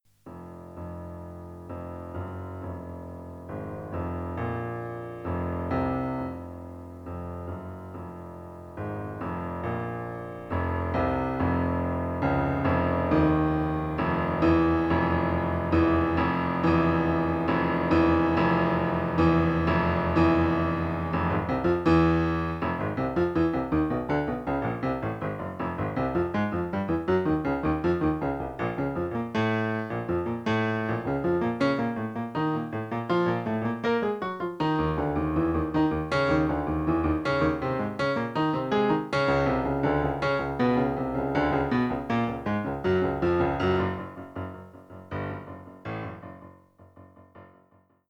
Instrumentation: Piano